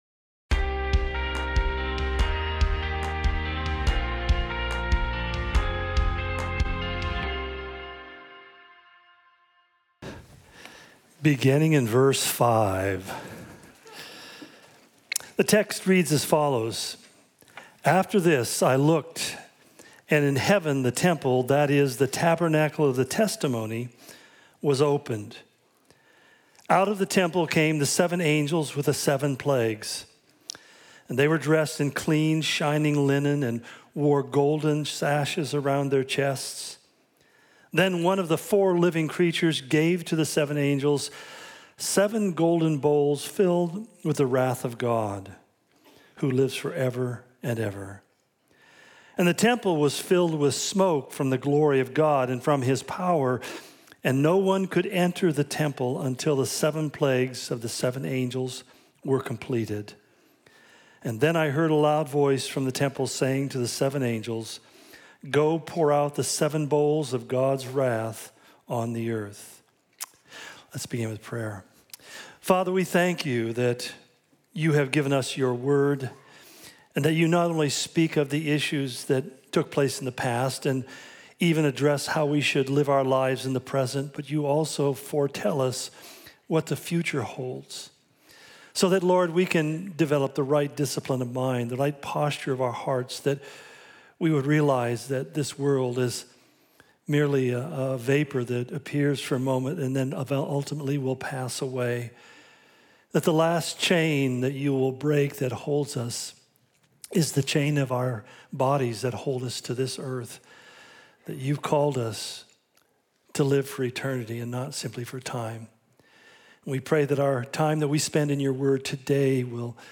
The Unveiling - Part 69 Justice! Calvary Spokane Sermon Of The Week podcast